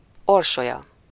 Orsolya (Hungarian pronunciation: [ˈorʃojɒ]
Hu-Orsolya.ogg.mp3